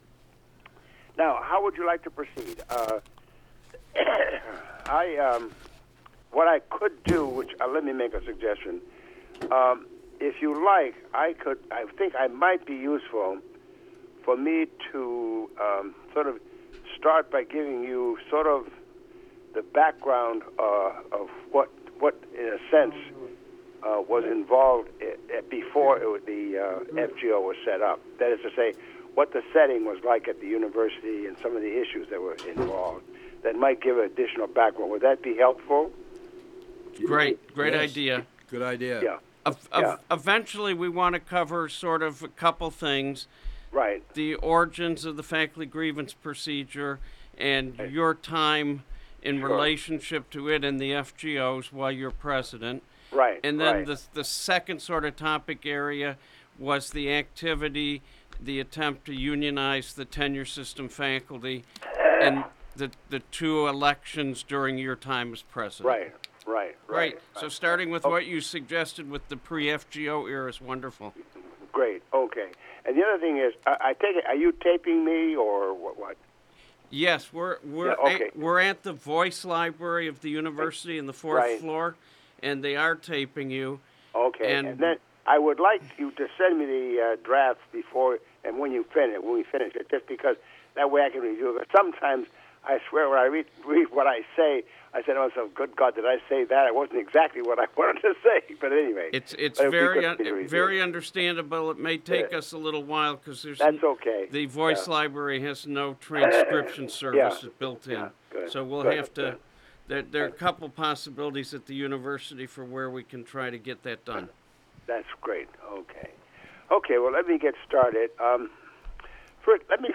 Interview of former Michigan State University President Clifton Wharton